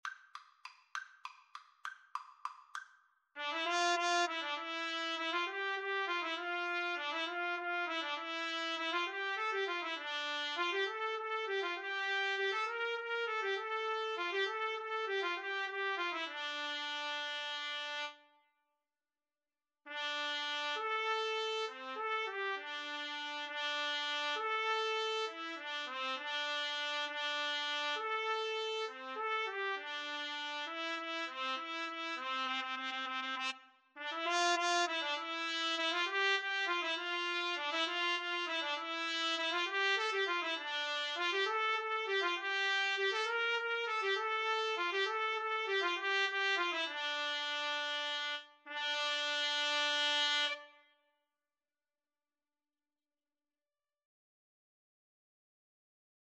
A film-score style piece
3/4 (View more 3/4 Music)
Fast and agressive =200
Film (View more Film Trumpet-Cello Duet Music)